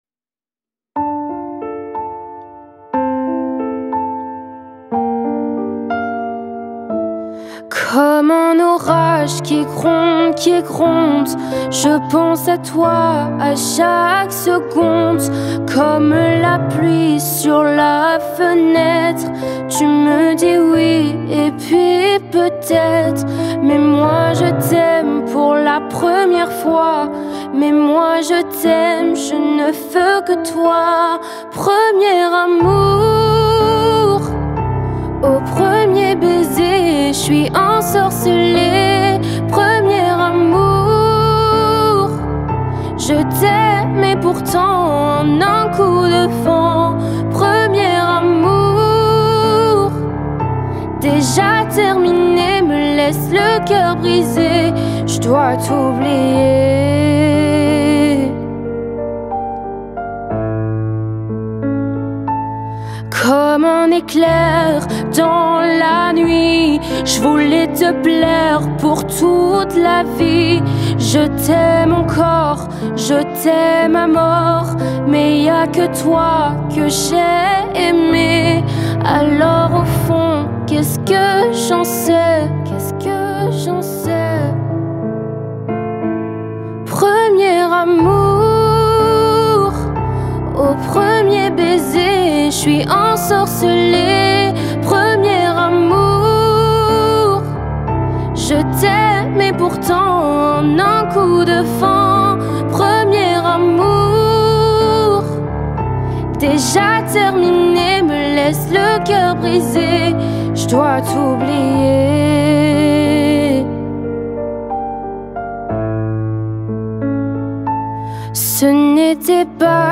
это нежная и трогательная песня